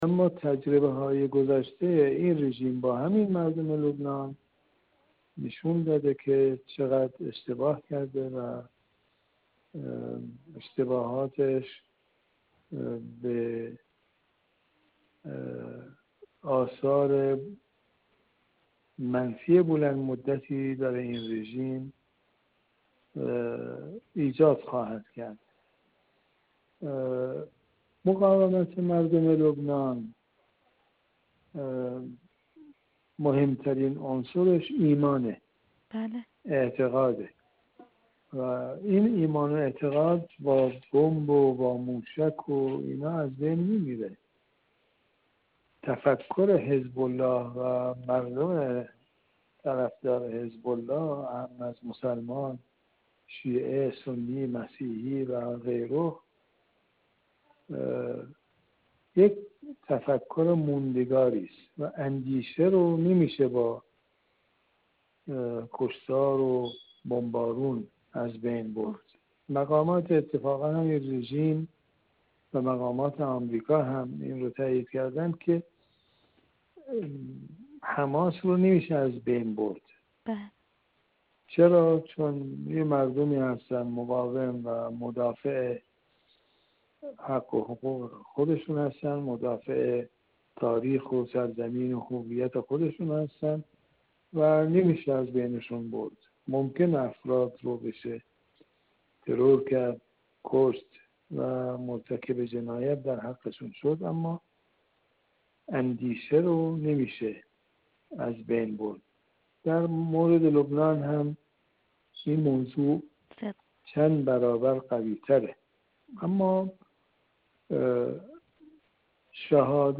کارشناس و تحلیلگر مسائل منطقه
گفت‌وگو